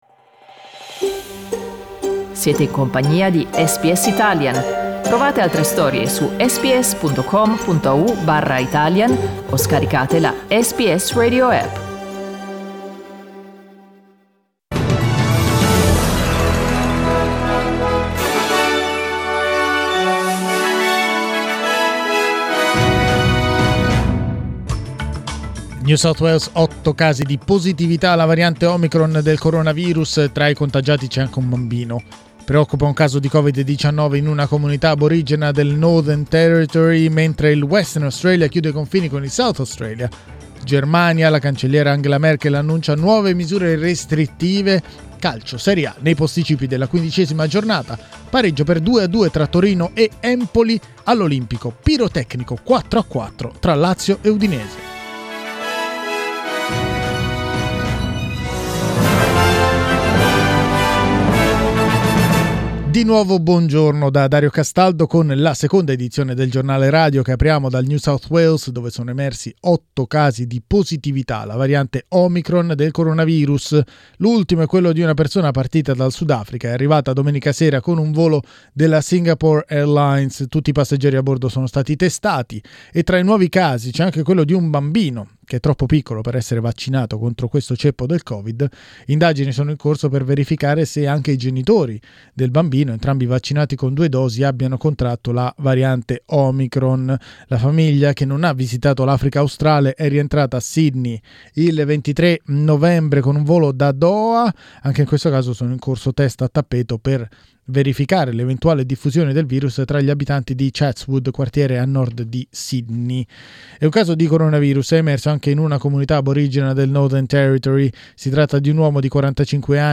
Giornale radio venerdì 3 dicembre 2021
Il notiziario di SBS in italiano.